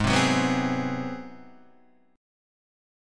G#7#9 chord {4 3 4 4 1 4} chord